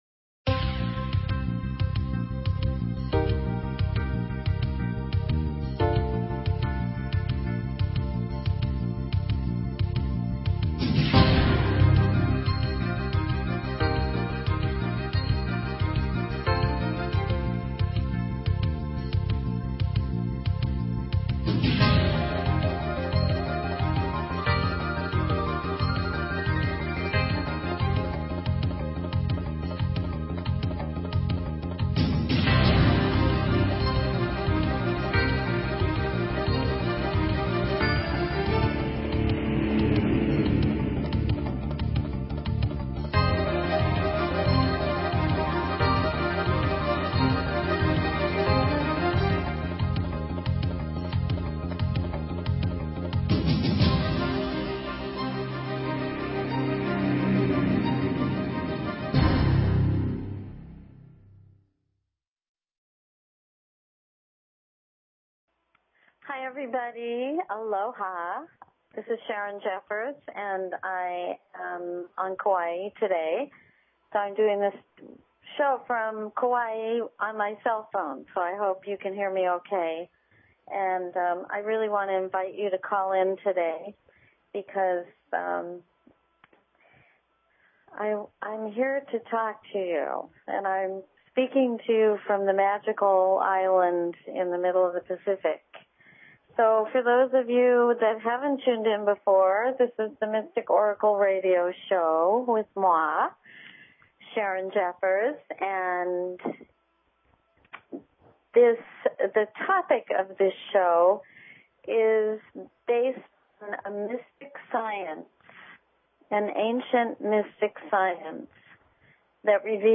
Talk Show Episode, Audio Podcast, The_Mystic_Oracle and Courtesy of BBS Radio on , show guests , about , categorized as
Open lines for calls.